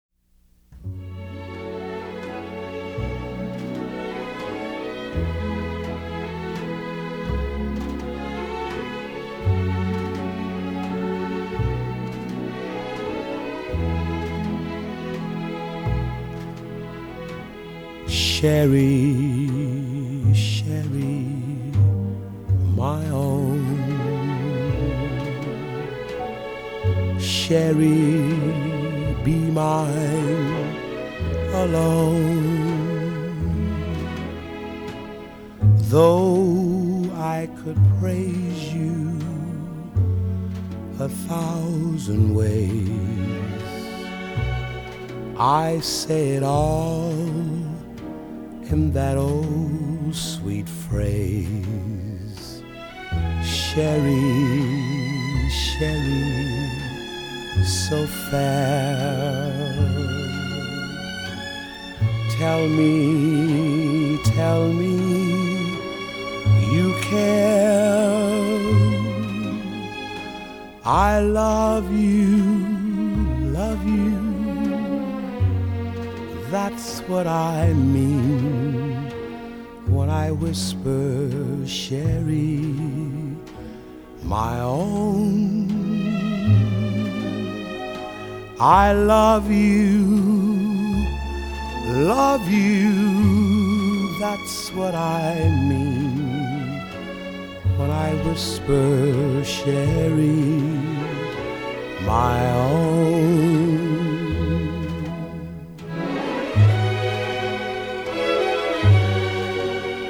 用深情無比的嗓音融化您的心！
採用原始類比母帶以最高音質之 45 轉 LP 復刻！